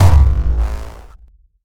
poly_explosion_nuke.wav